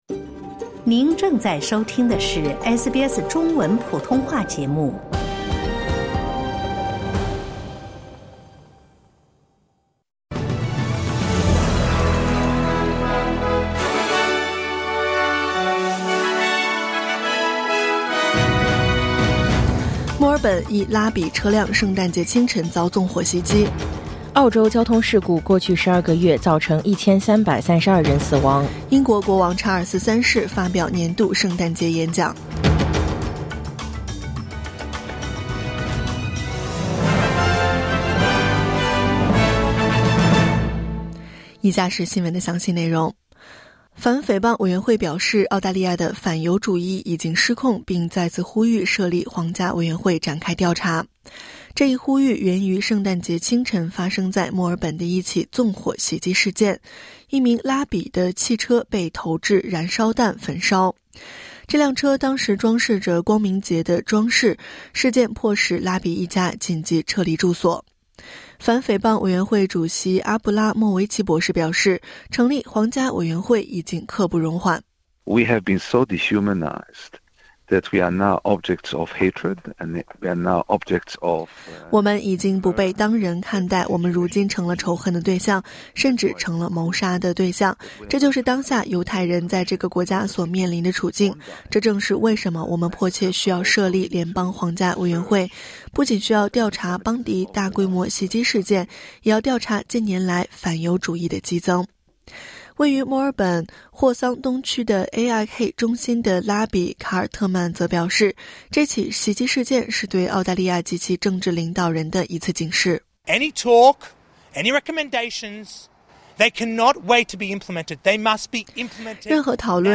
SBS早新闻（2025年12月26日）